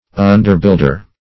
Search Result for " underbuilder" : The Collaborative International Dictionary of English v.0.48: Underbuilder \Un"der*build`er\, n. A subordinate or assistant builder.